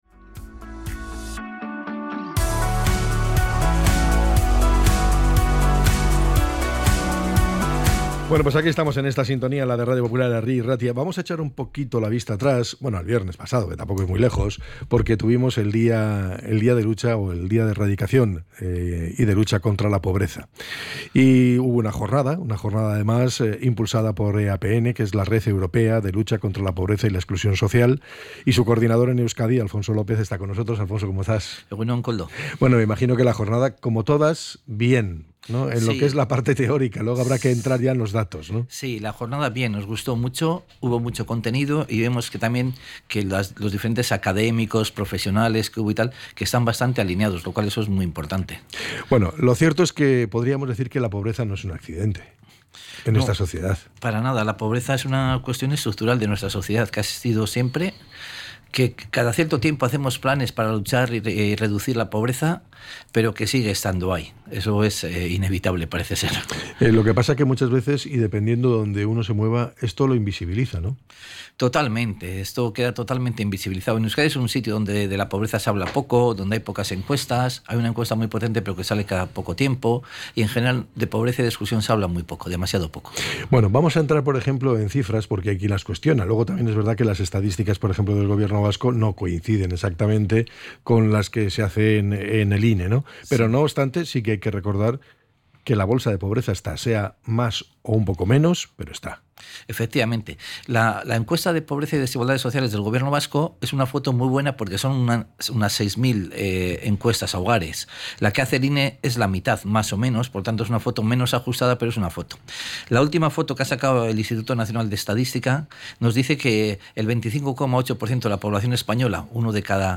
ENTREV.-EAPN.mp3